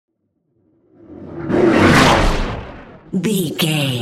Whoosh fast airy cinematic
Sound Effects
Fast
whoosh